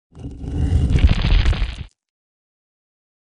音效